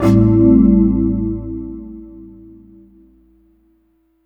59 CHORD 1-L.wav